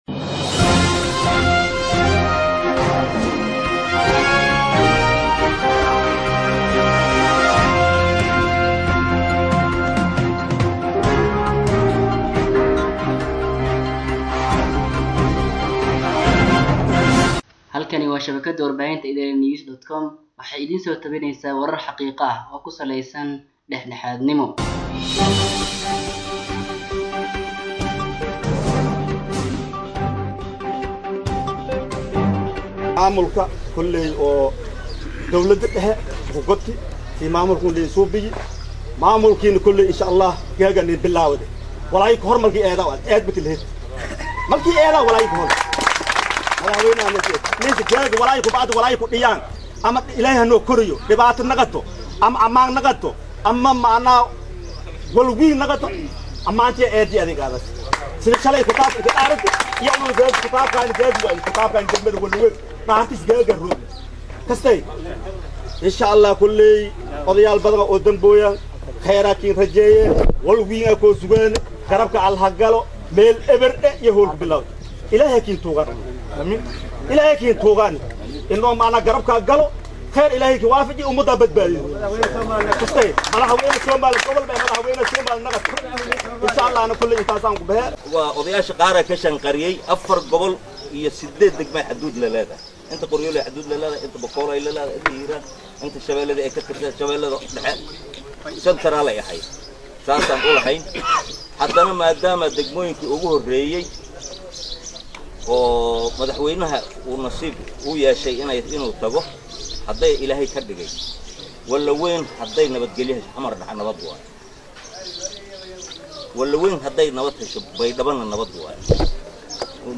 Halkan Ka Dhageyso, Hadaladii Odayaasha Wanleweyne